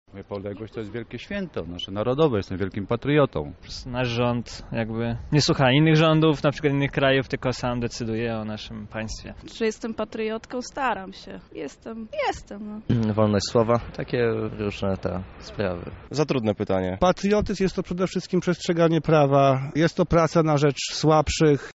Zapytaliśmy także mieszkańców miasta, czym dla nich jest niepodległość.